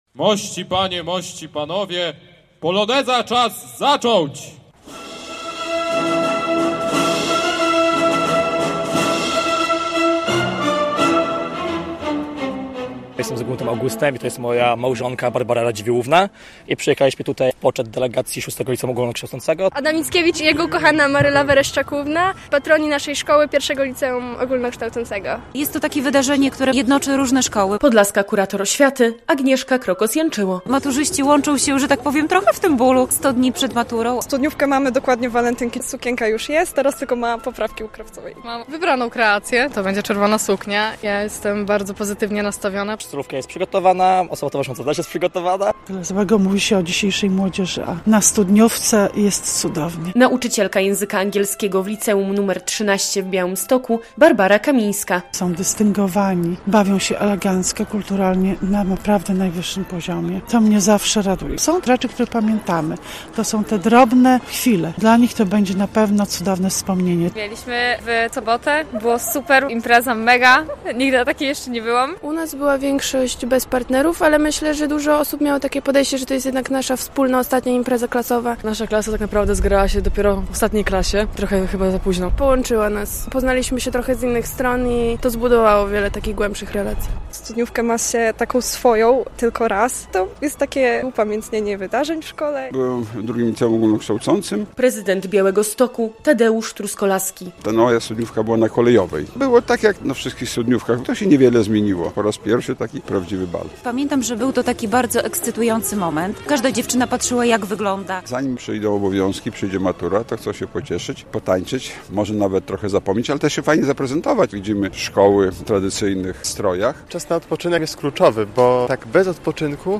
Było uroczyście i kolorowo. Po raz 16. białostoccy maturzyści spotkali się na Rynku Kościuszki, by wspólnie zatańczyć poloneza.